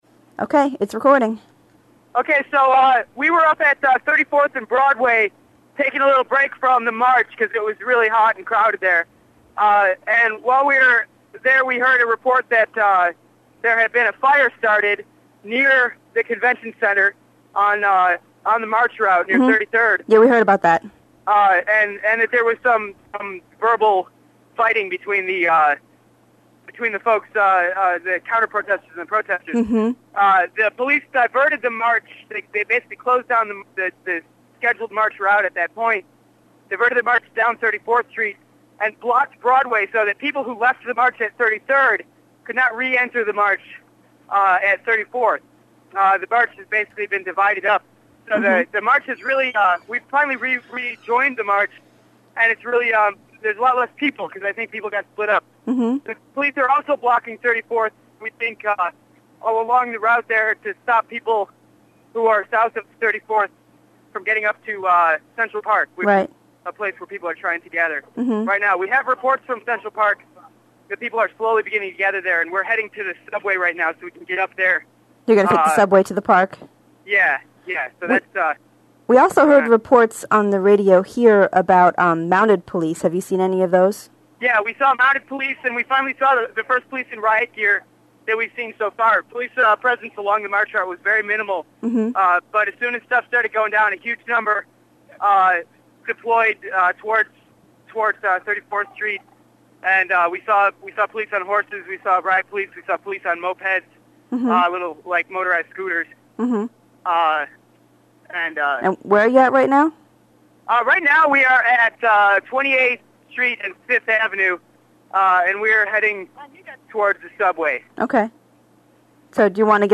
Second report in from UFPC march in NYC